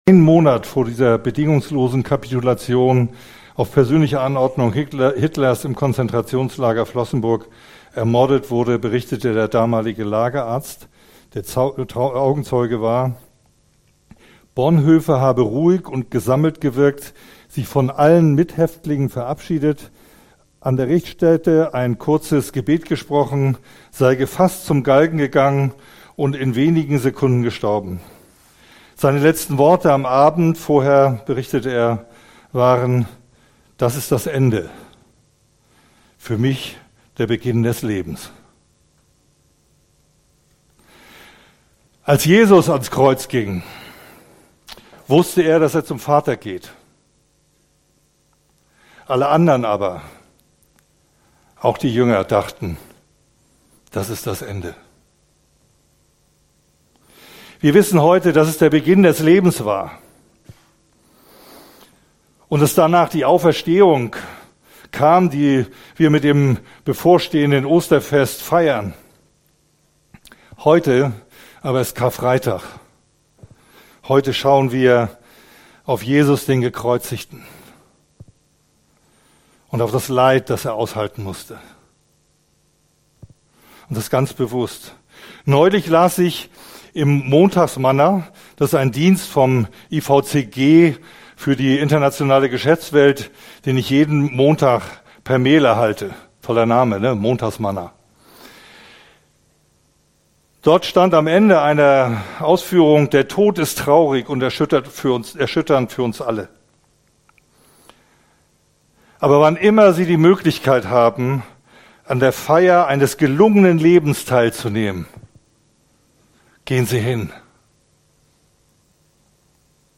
Serie: Predigt Gottesdienst: Sonntag